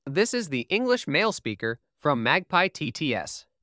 🔉EN-US.Male.Male-1
EN-US.Male.Male-1_MagpieTTS.wav